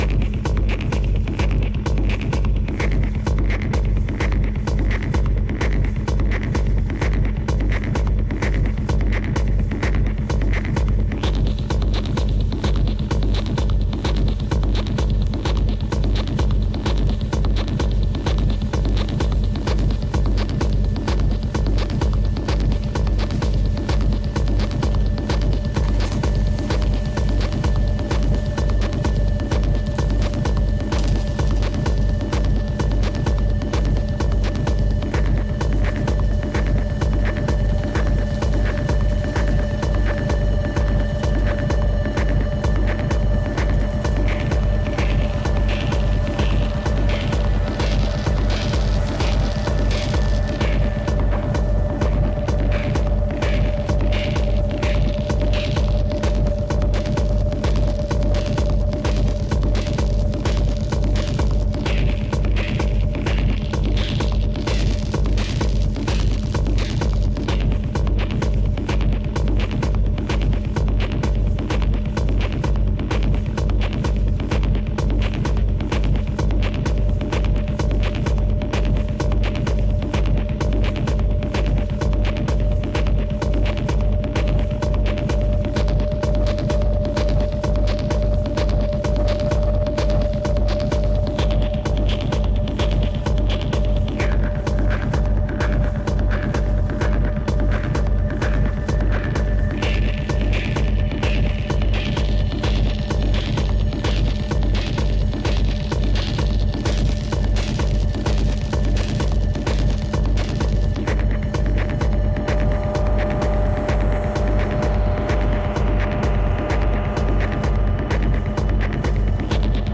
Harsh, rough and noisy Techno cuts